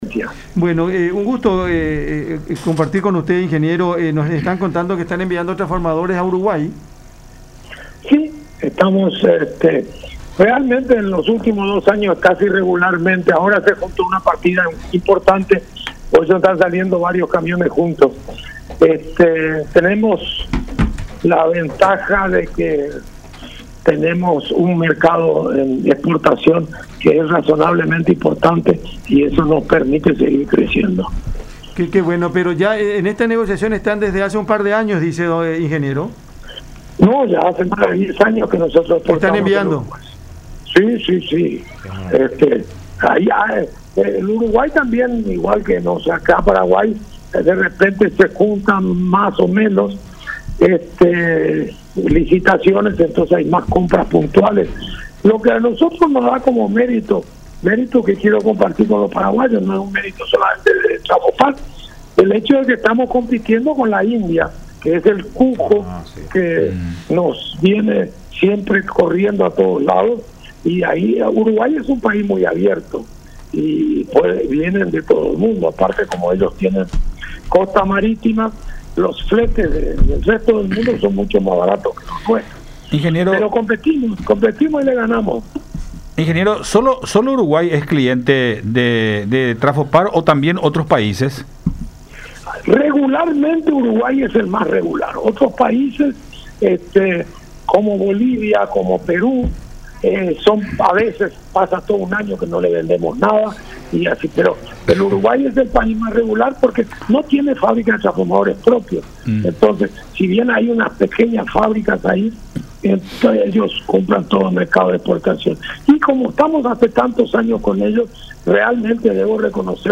en charla con Cada Mañana por La Unión.